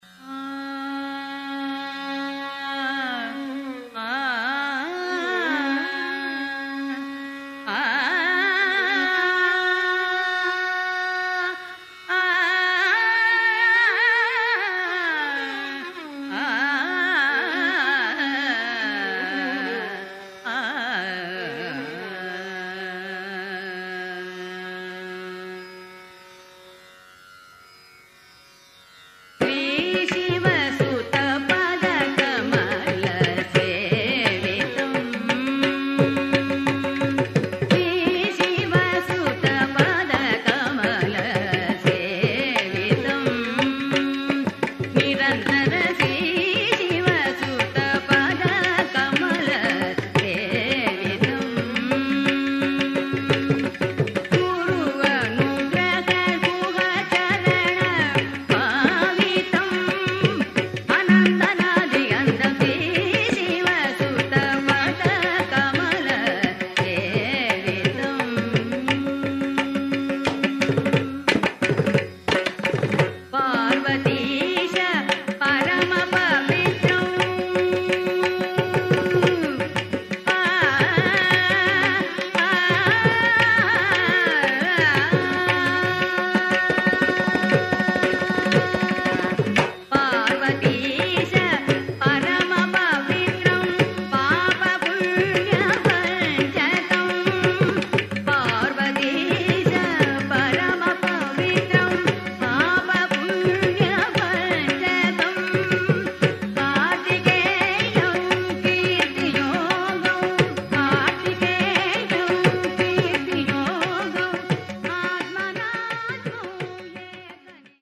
ഗായിക